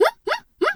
pgs/Assets/Audio/Animal_Impersonations/zebra_whinny_09.wav at master
zebra_whinny_09.wav